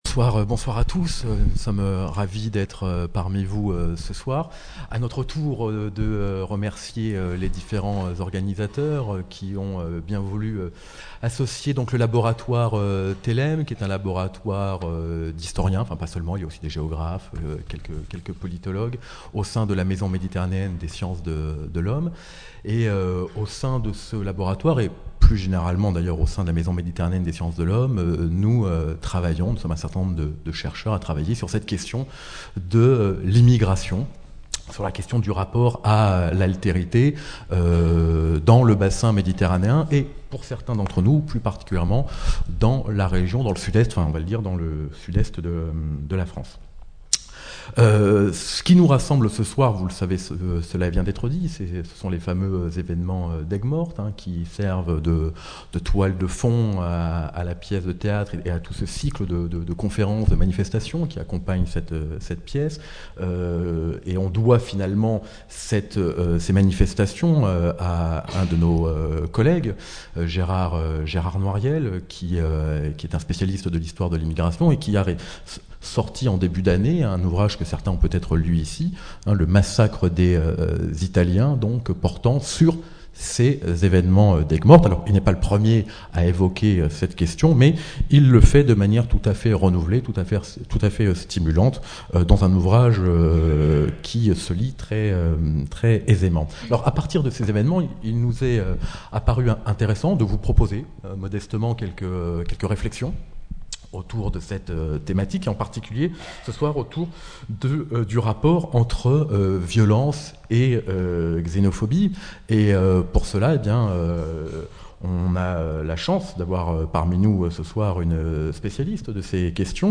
Conférence
Rencontre littéraire